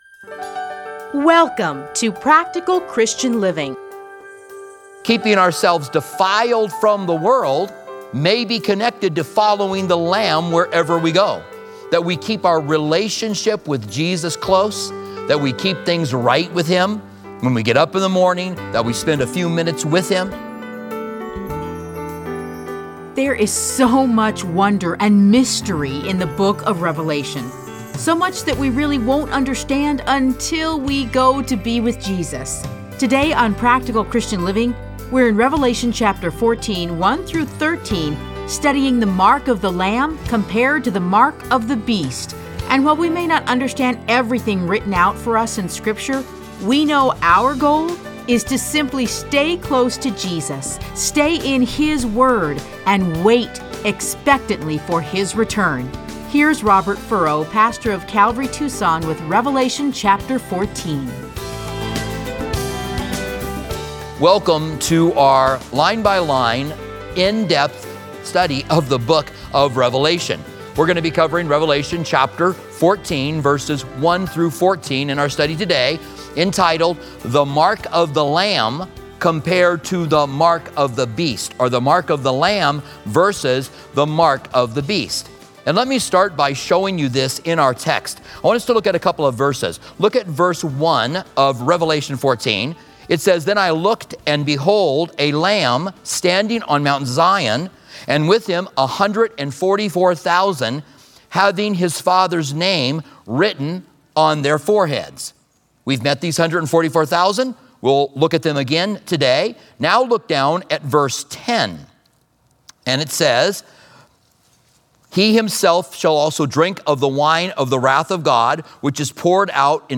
Listen to a teaching from Revelation 14:1-13.